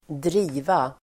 Uttal: [²dr'i:va]